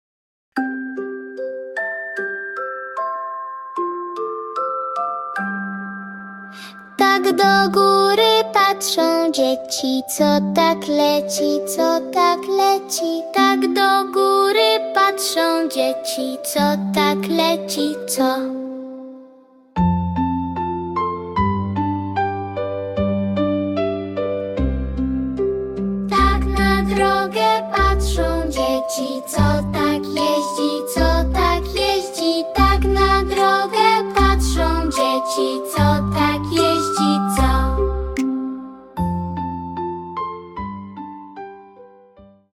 • podkład muzyczny ze ścieżką wokalną,